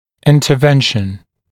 [ˌɪntə’venʃn][ˌинтэ’вэншн]вмешательство